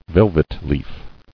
[vel·vet·leaf]